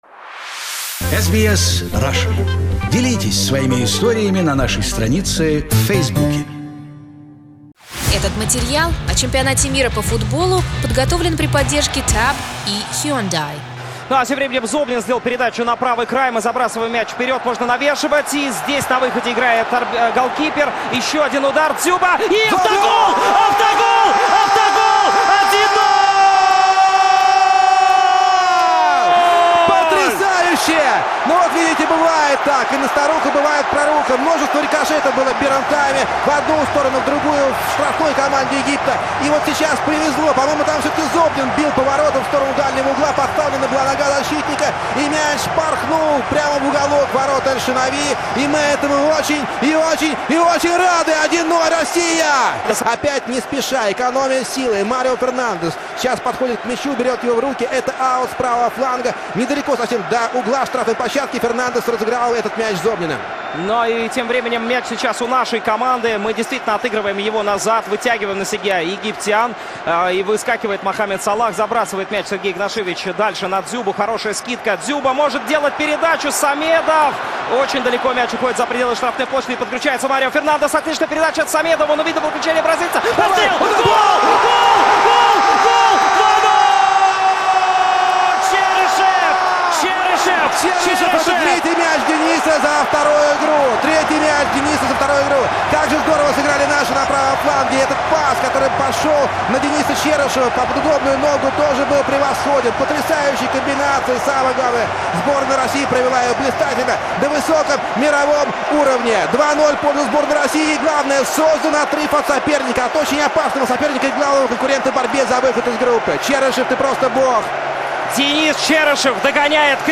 Australian fans of the Russian team at the FIFA World Cup had a lucky opportunity to listen to the commentary of Russia against Egypt in Russian on SBS Radio 1.